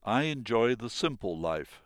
Sounds for Exercise I Chapter 4 spoken by a British Speaker